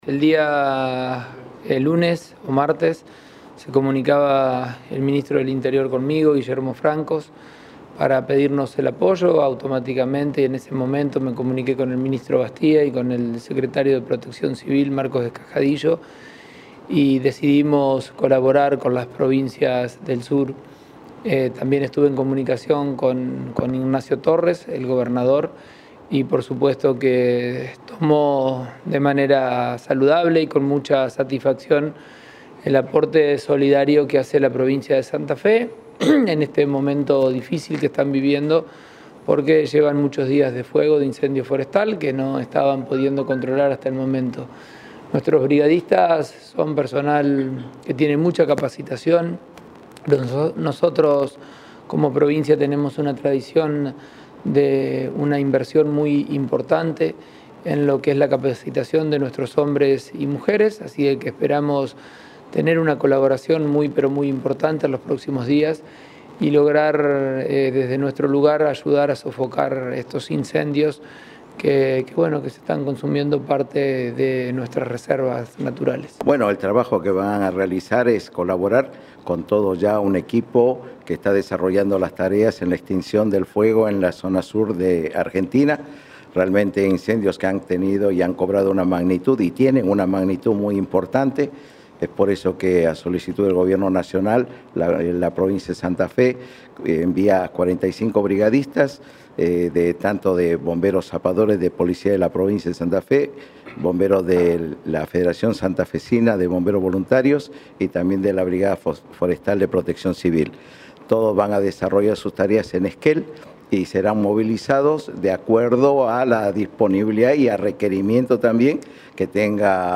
Esta mañana el mandatario estuvo en el aeropuerto, para despedirlos.